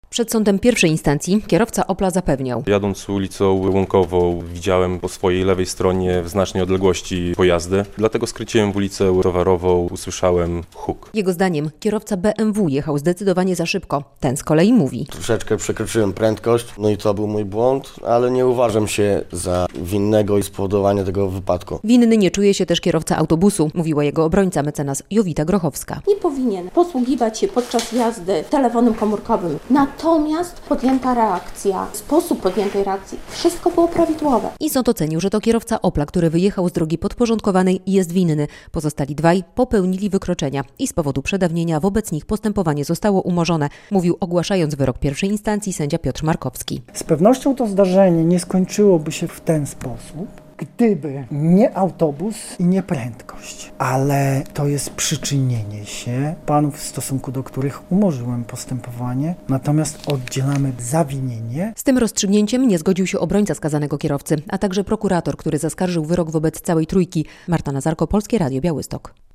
Śmiertelny wypadek na ul. Towarowej w Białymstoku - będzie proces apelacyjny - relacja